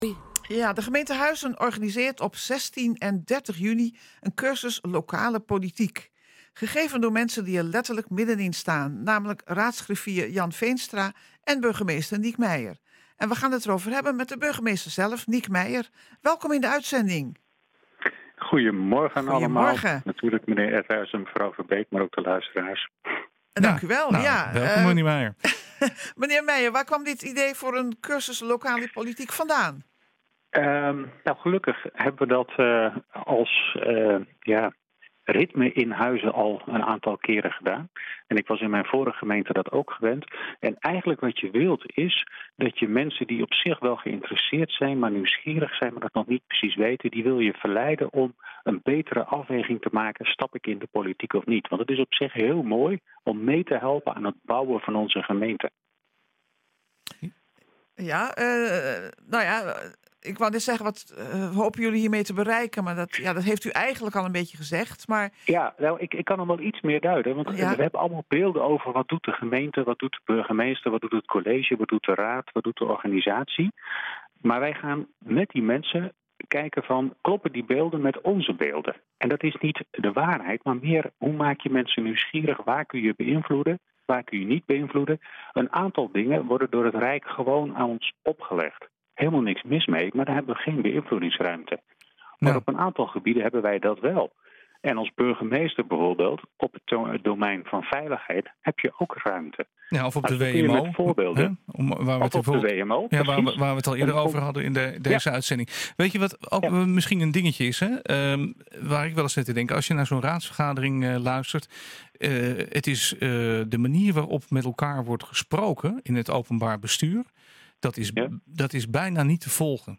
We gaan het erover hebben met de burgemeester zelf, Niek Meijer.